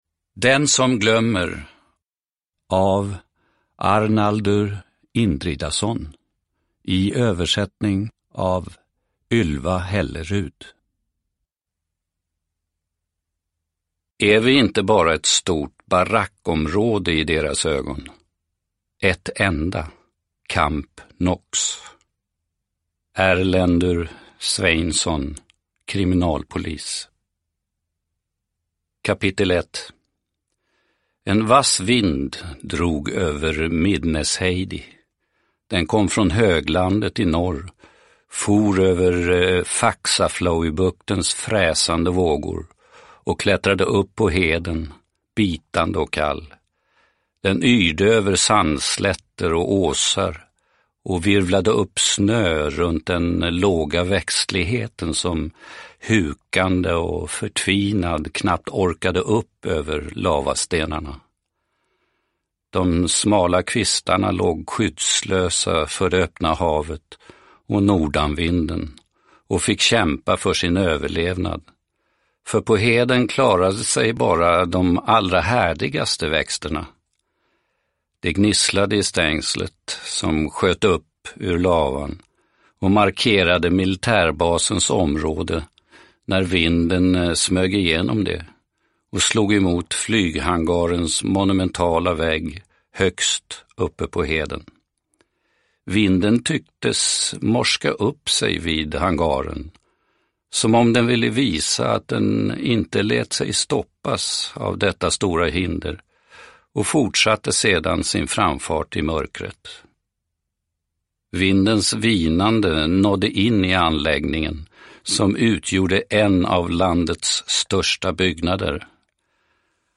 Den som glömmer – Ljudbok – Laddas ner